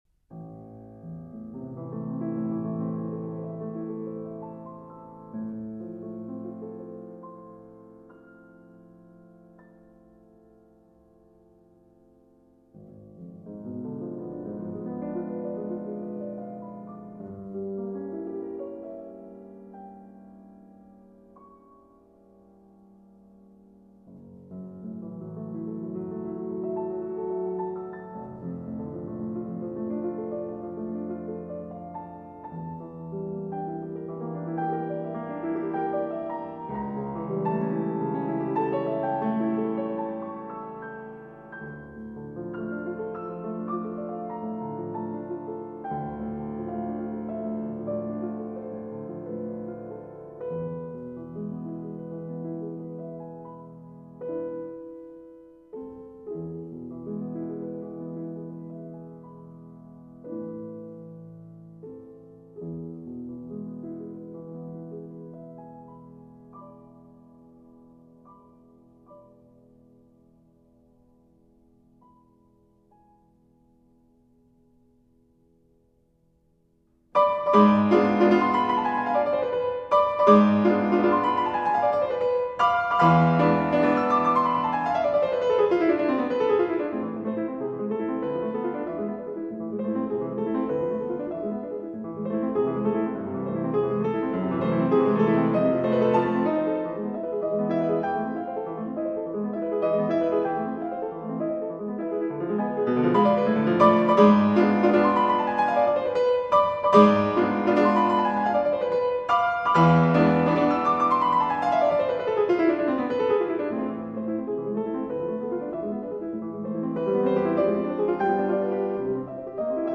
Who is the composer of this piano piece?
It beginns with slow arpeggios and then turns to a great Allegro.
klavierstueck.mp3